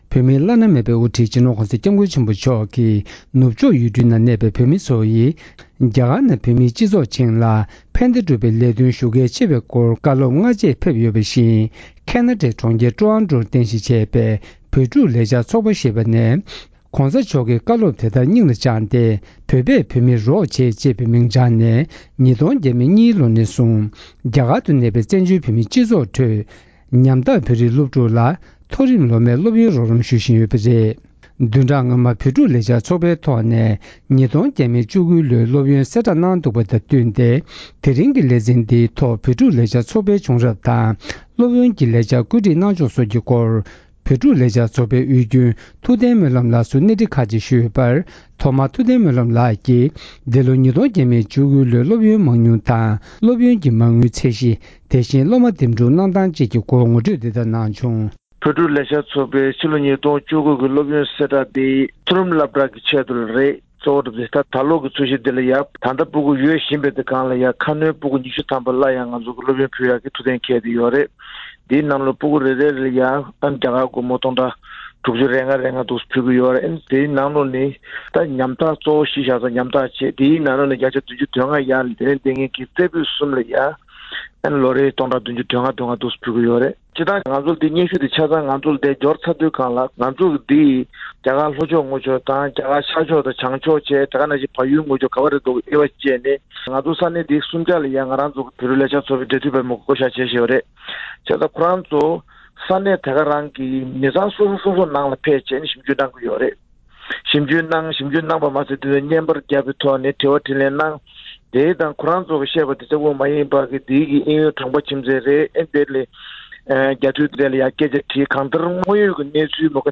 བོད་ཕྲུག་ལས་འཆར་ཚོགས་པས་༢༠༡༩ལོའི་སློབ་ཡོན་གསལ་བསྒྲགས་གནང་བ་དང་འབྲེལ་བོད་ཕྲུག་ལས་འཆར་ཚོགས་པའི་བྱུང་རིམ་དང་སློབ་ཡོན་སྐོར་བཅར་འདྲི་ཞུས་པ།